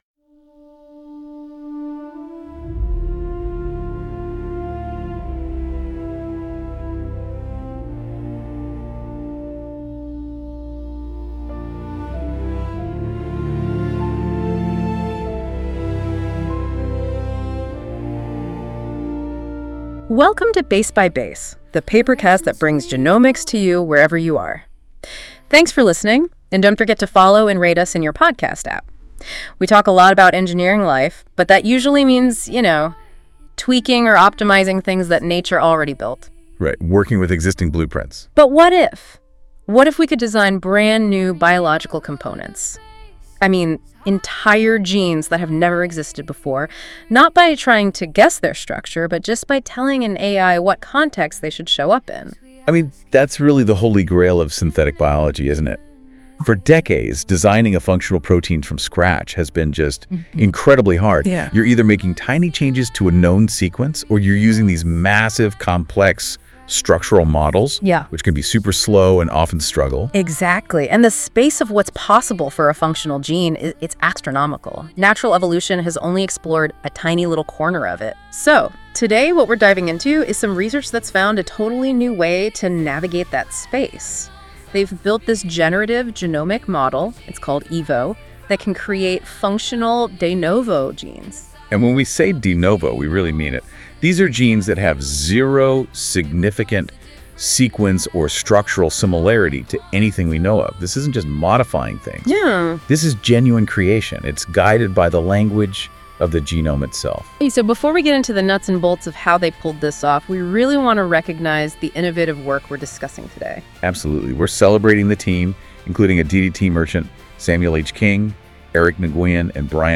Semantic Design of de novo Genes with Evo Music:Enjoy the music based on this article at the end of the episode.